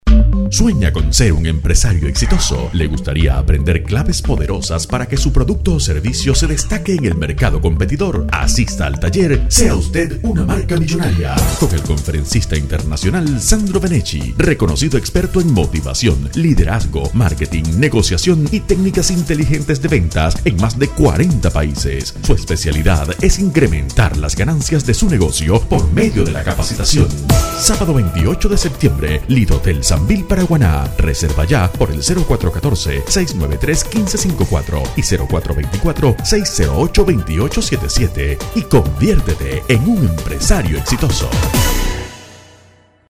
spanisch Südamerika
Sprechprobe: Werbung (Muttersprache):
Voice Over in Neutral Spanish